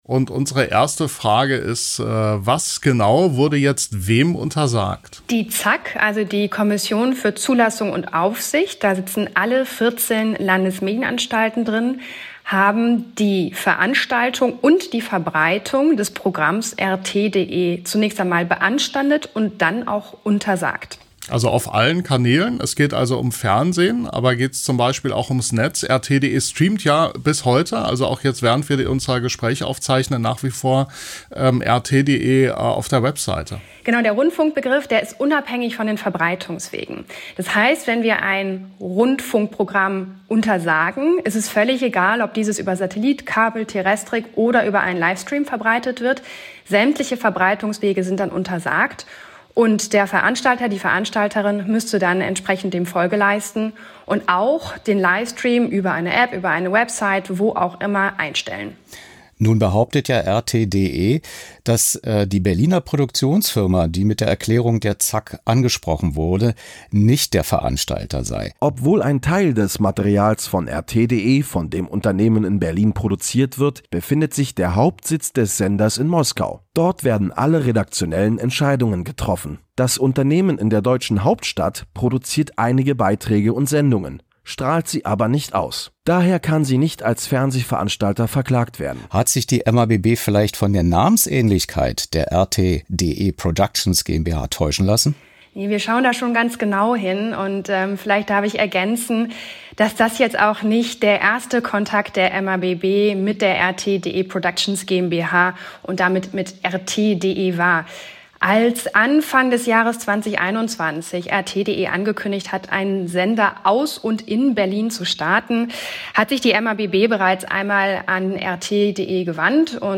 Freier Medienjournalist